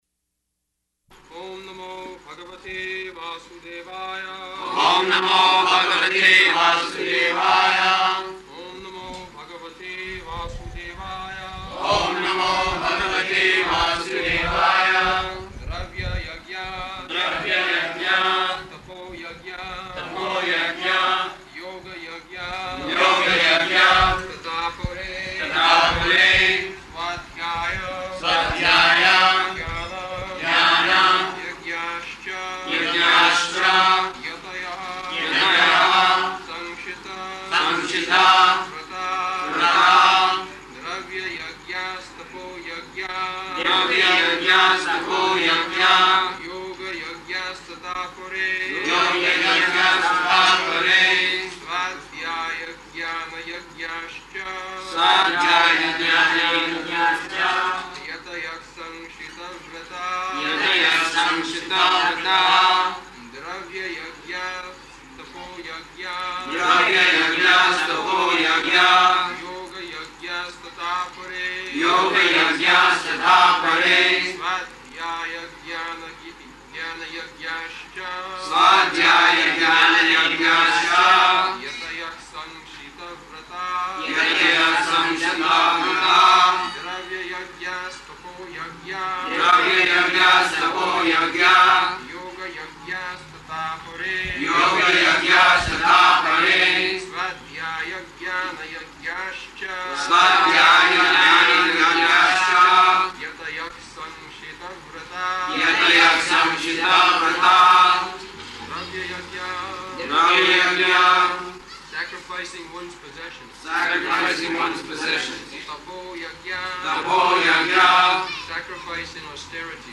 April 17th 1974 Location: Bombay Audio file
[Prabhupāda and devotees repeat] [leads chanting of verse]